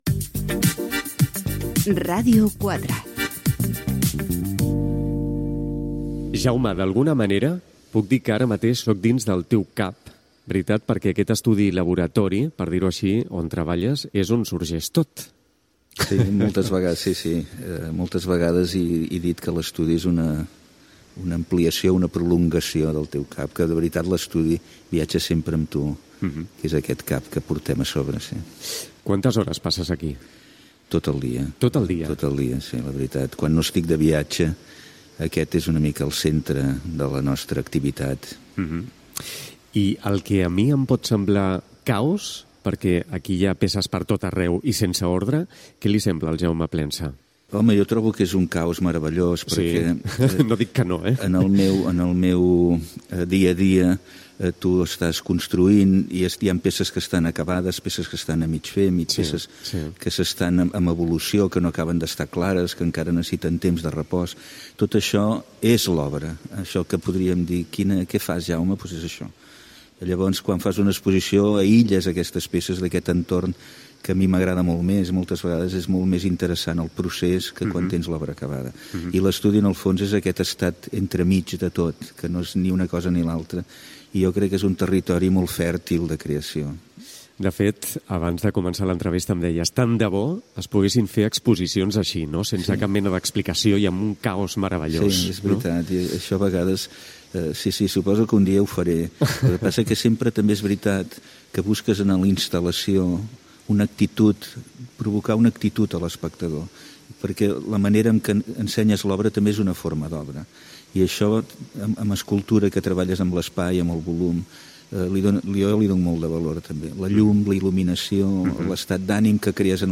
Indicatiu de la ràdio, entrevista a l'artista, escultor i gravador Jaume Plensa.
FM